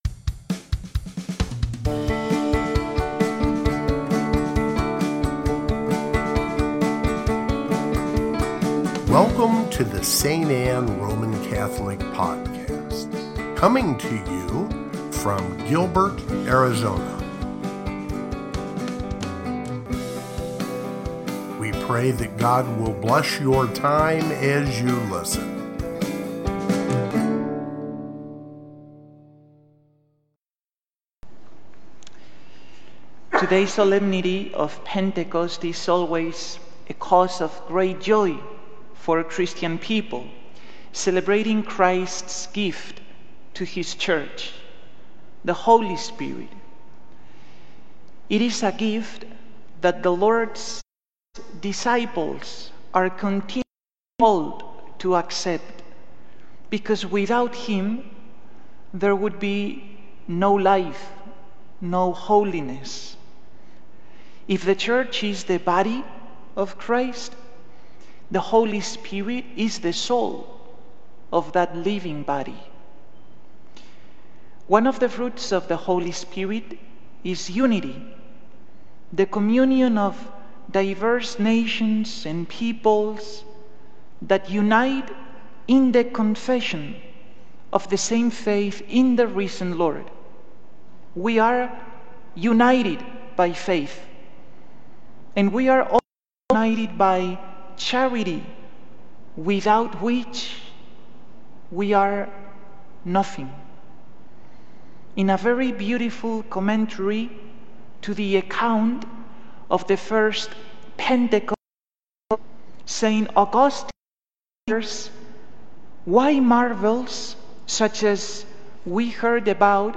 CORPUS CHRISTI HOMILY Read more